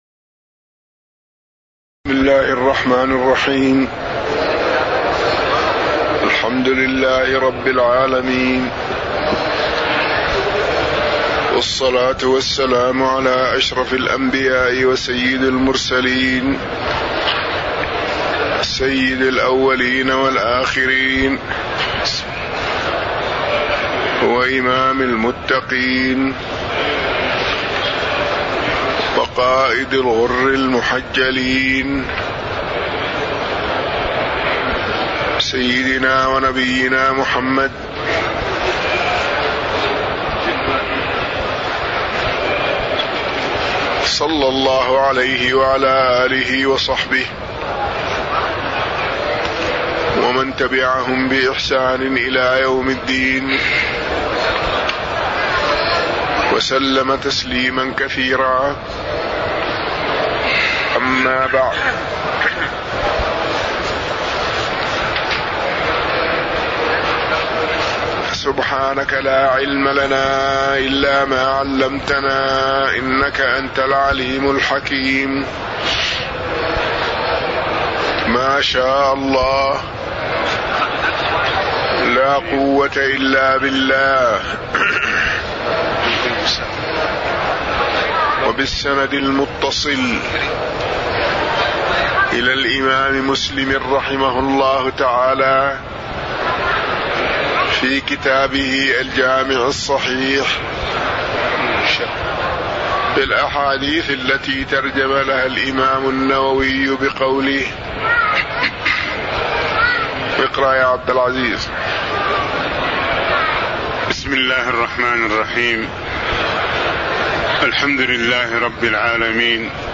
تاريخ النشر ٢٠ شوال ١٤٣٧ هـ المكان: المسجد النبوي الشيخ